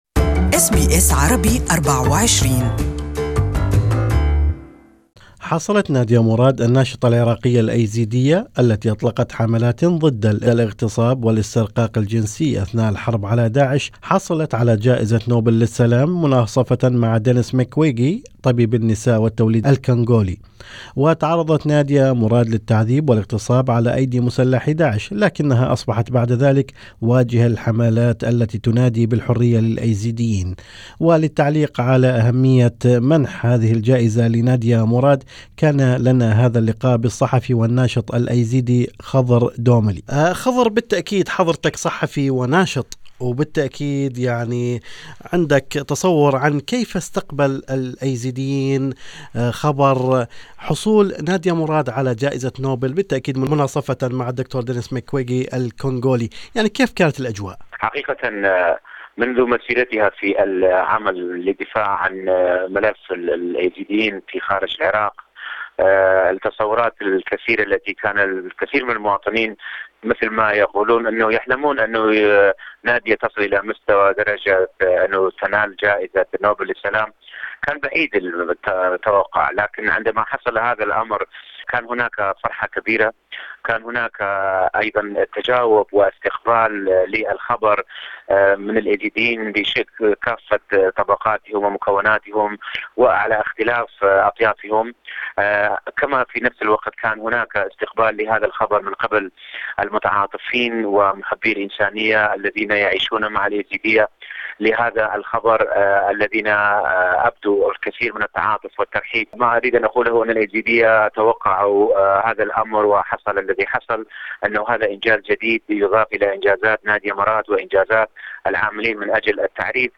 ناشط ايزيدي يعمل مع النساء الايزيديات الناجيات من داعش يصف نادية مراد بانها رمز لهن